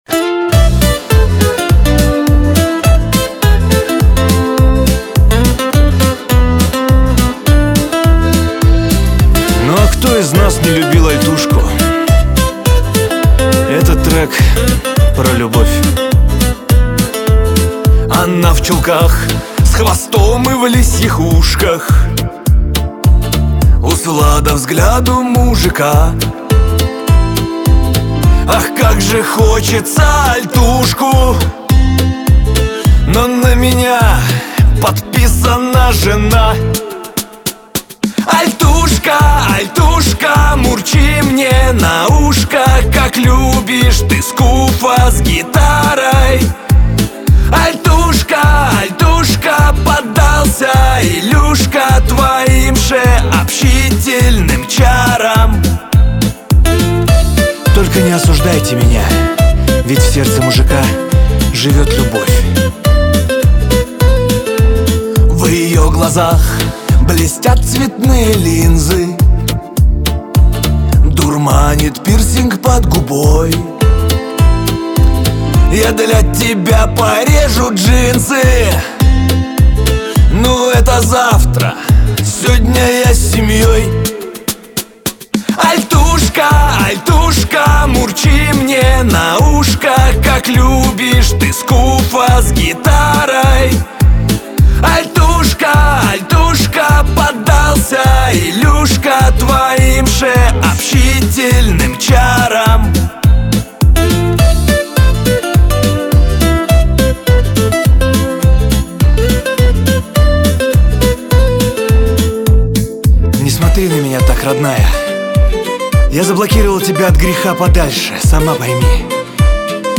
Качество: 320 kbps, stereo
Поп музыка, Русские поп песни